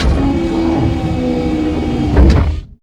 CargoNetLower.wav